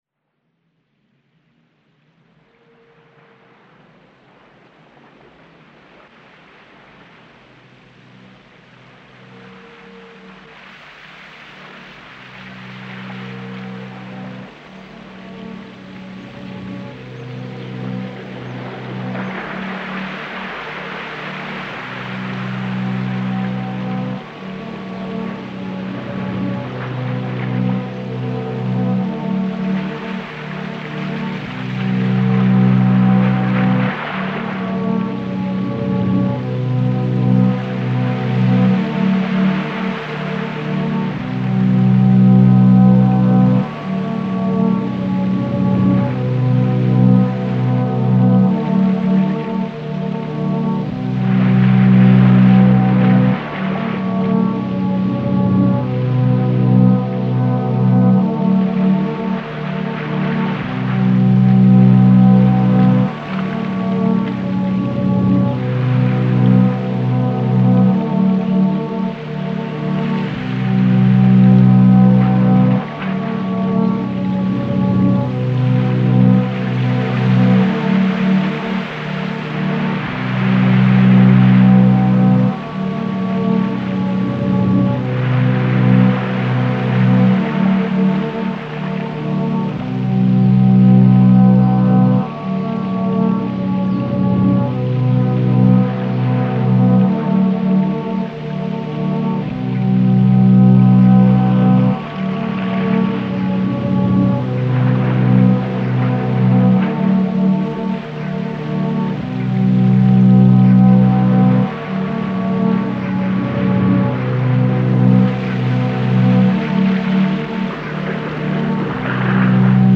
File under: Ambient